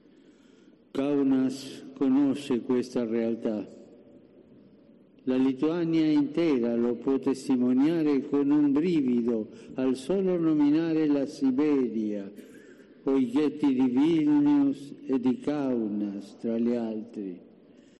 Francisco recuerda en la misa en Kaunas los horrores de la ocupación soviética